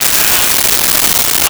Alien Wierdness.wav